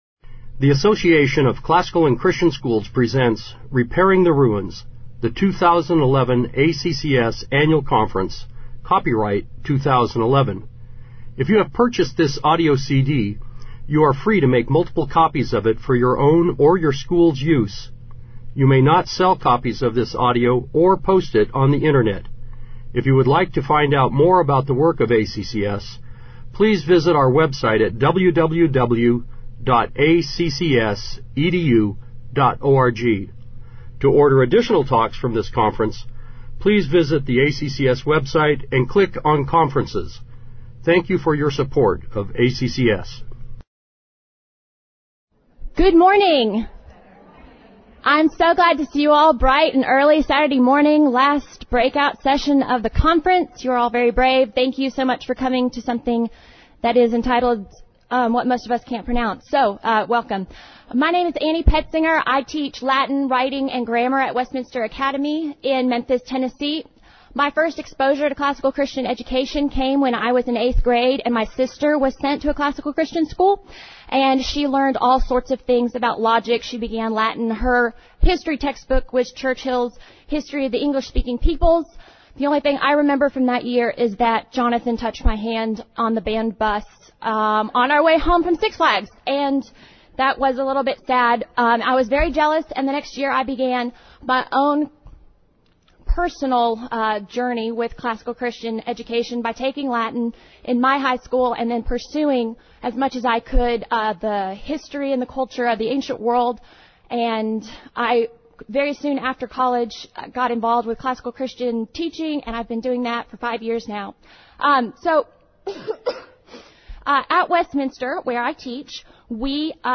2011 Foundations Talk | 0:55:18 | 7-12, General Classroom
Jan 27, 2019 | 7-12, Conference Talks, Foundations Talk, General Classroom, Library, Media_Audio | 0 comments
Speaker Additional Materials The Association of Classical & Christian Schools presents Repairing the Ruins, the ACCS annual conference, copyright ACCS.